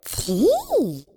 speech_rodent_3.wav